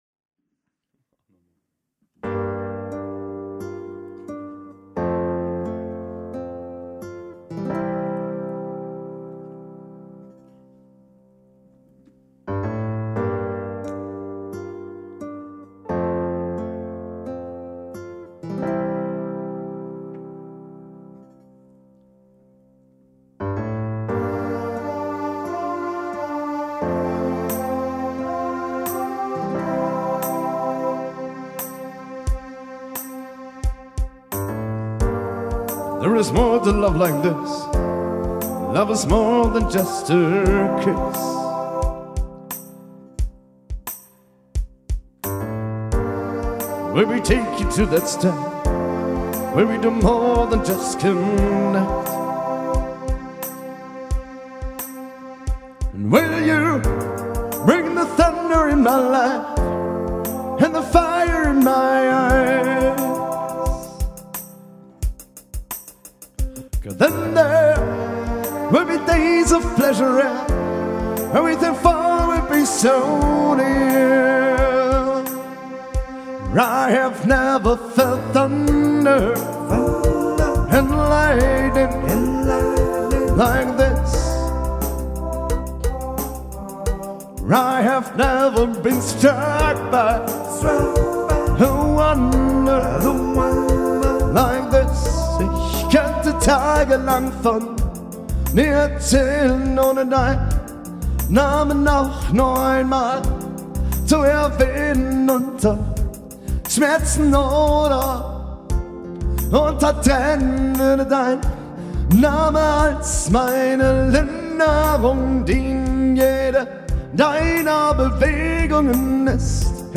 Pop: